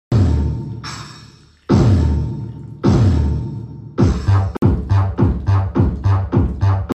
8 Inch Powerful Speaker Bass, Sound Effects Free Download